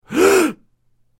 Scared-man-sound-effect.mp3